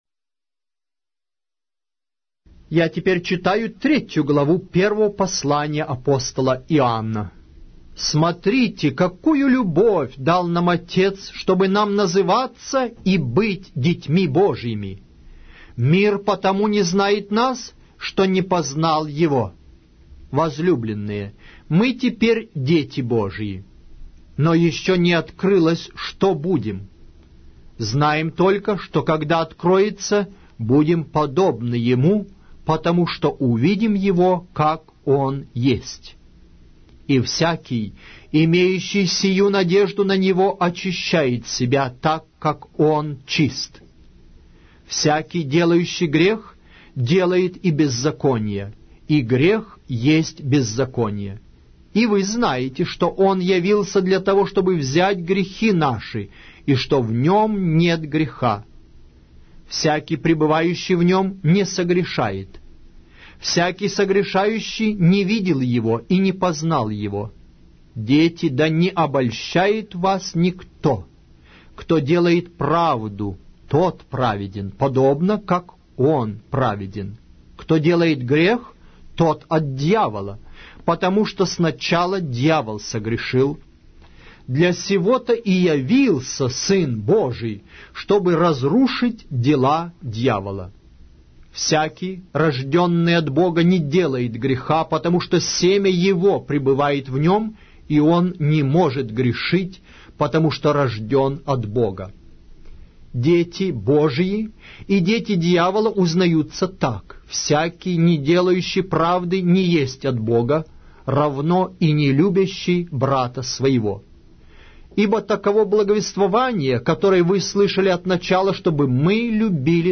Аудиокнига: 1-е послание Апостола Иоанна